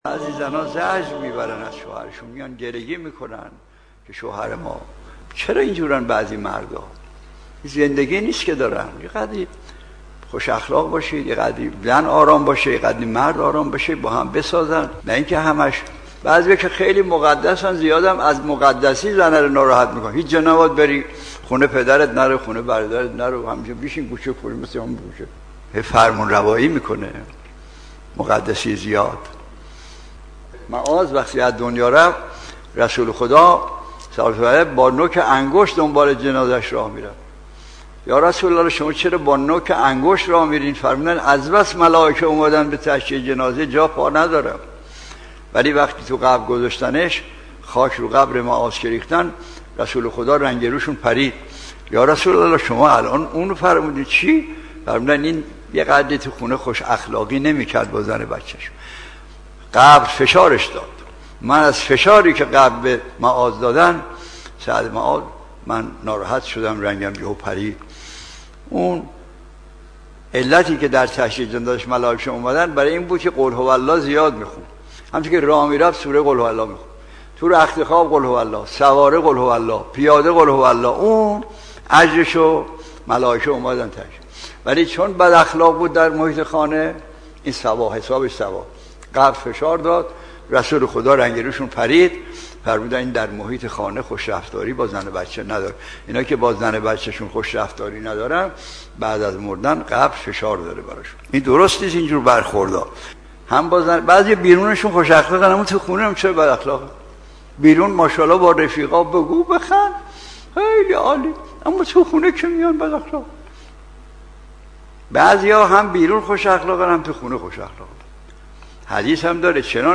مجموعه حاضر سلسله درس های اخلاق و احکام آیت الله مجتهدی تهرانی می باشد .